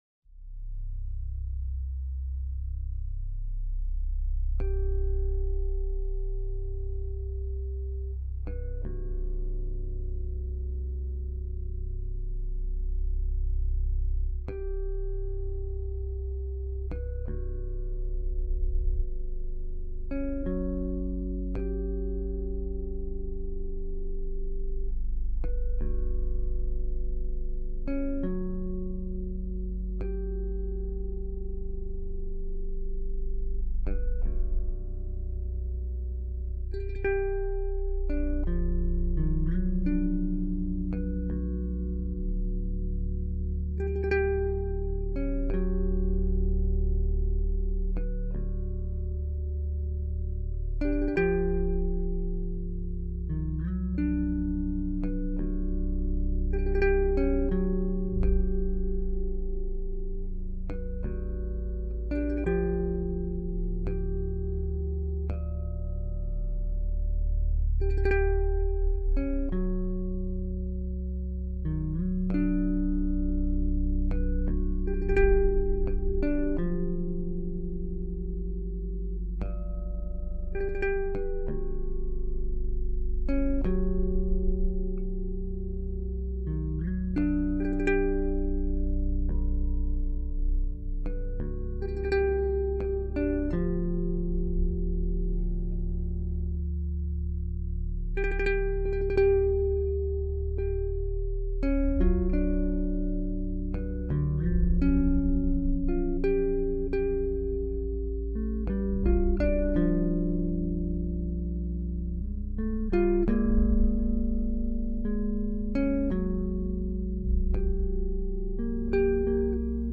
中阮、竖笛、打击乐、电贝司、弦子、男声
录音地点：上海广播大厦一号录音棚